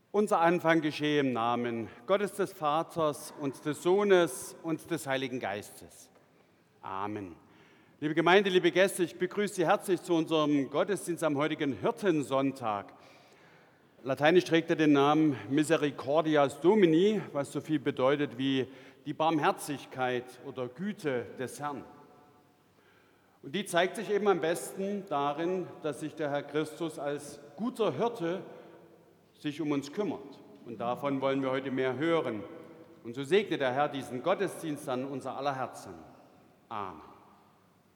Audiomitschnitt unseres Gottesdienstes vom 2. Sonntag nach Ostern 2025.